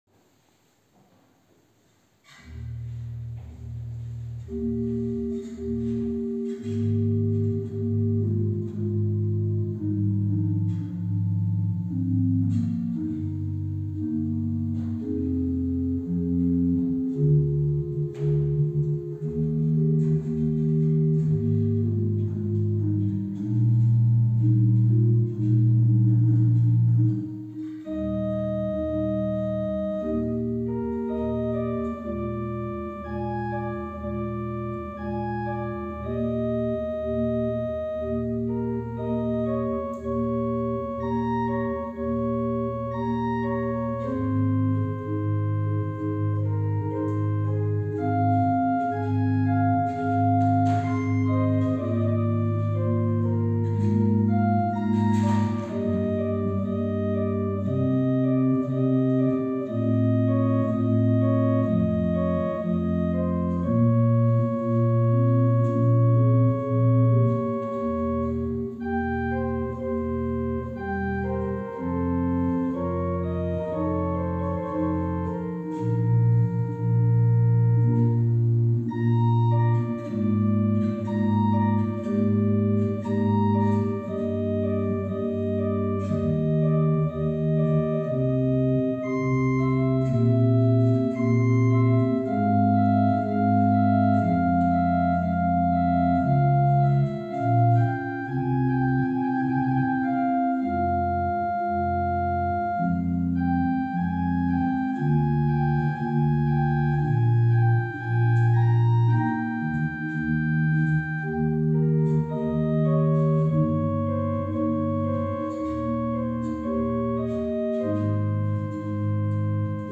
Gottesdienst aus der reformierten Erlöserkirche, Wien-Favoriten, 22. November 2020, Ewigkeitssonntag (Gedenken an die Verstorbenen)